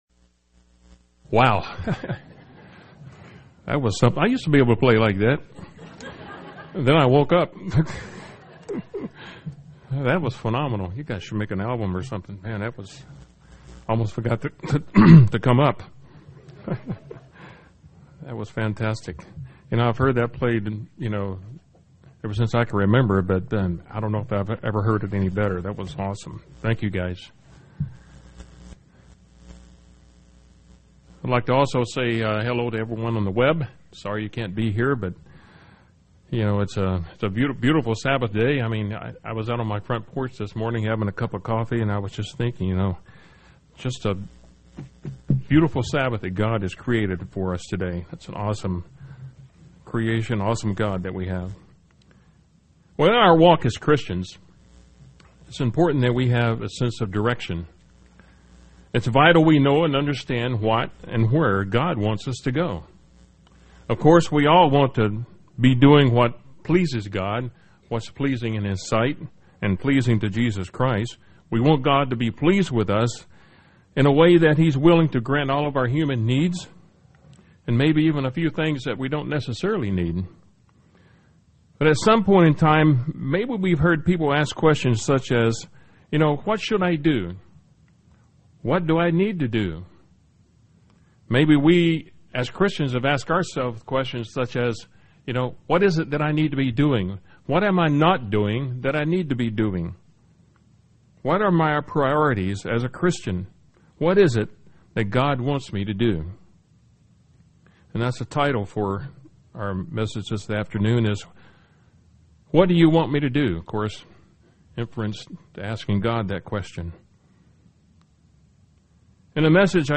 Given in Tampa, FL
UCG Sermon Studying the bible?